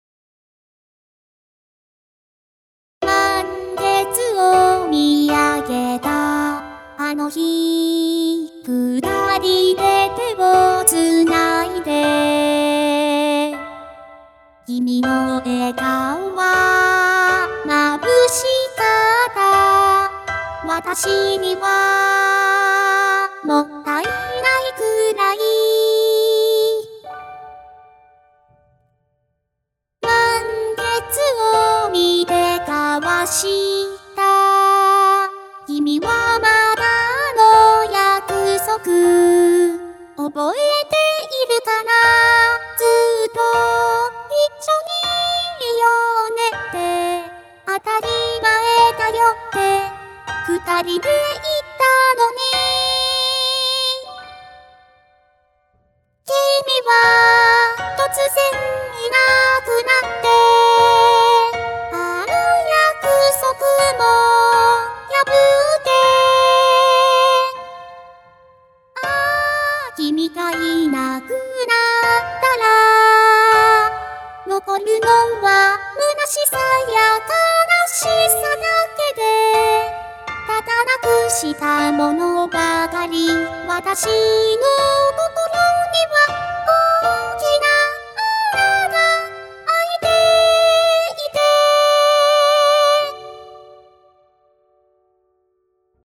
ワンコーラスデモバージョン（初音ミク）
アイデアが浮かぶように、ピアノのコードと歌だけで、まだシンプルな状態です。
MIKU.mp3